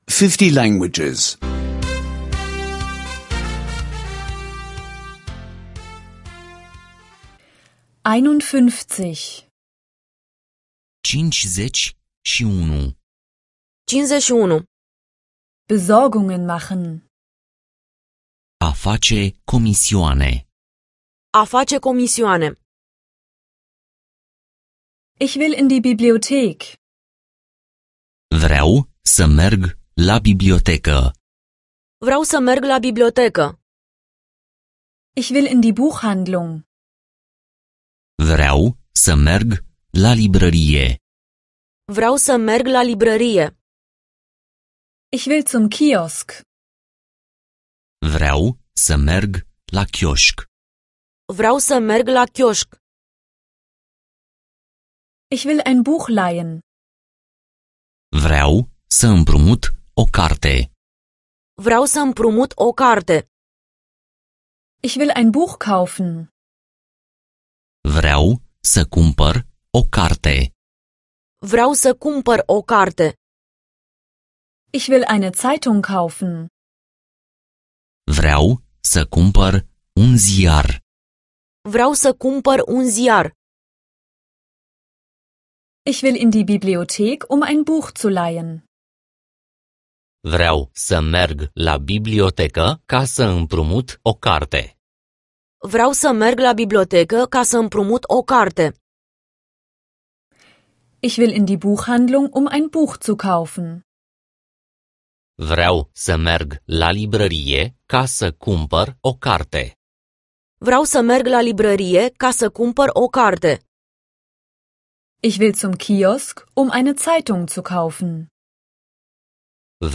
Rumänischen Audio-Lektionen, die Sie kostenlos online anhören können.